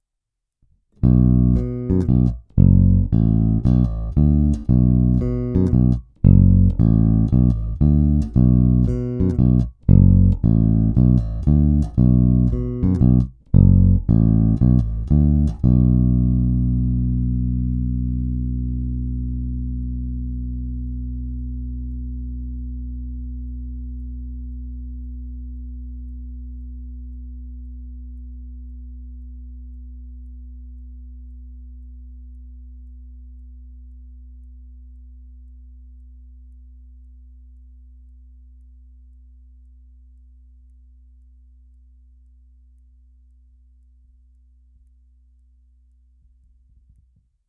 Zvuk je trochu nezařaditelný. Dobrý, pevný, ale charakter Music Manu v něm není ani v náznaku, což jsem upřímně řečeno vlastně vůbec neočekával. Originál Music Man snímač a aktivní elektronika jsou prostě téměř nenahraditelné. Tato aktivní elektronika poskytuje hodně čistý, ničím nezabarvený zvuk, a nutno dodat, že má hodně silný výstup, velký zdvih.